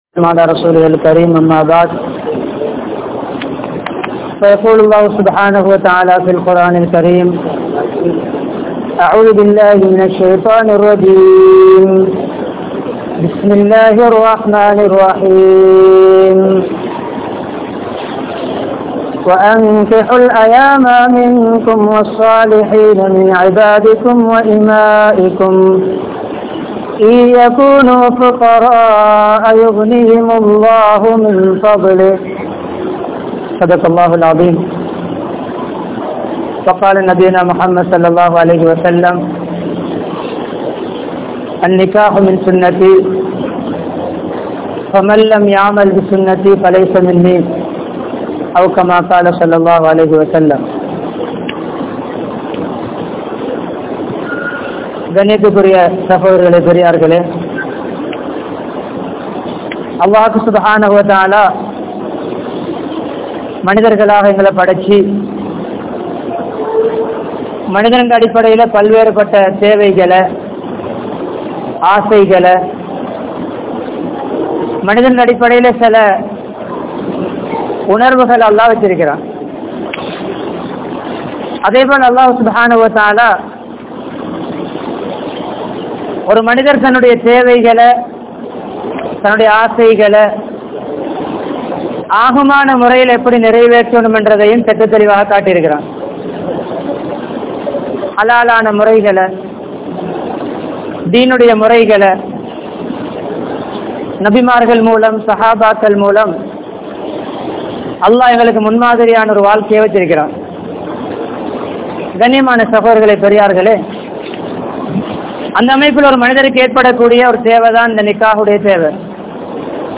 Mun Maathiriyaana Kanvan (முன்மாதிரியான கனவன்) | Audio Bayans | All Ceylon Muslim Youth Community | Addalaichenai
Minnan Jumua Masjith